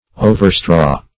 Overstraw \O`ver*straw"\, v. t.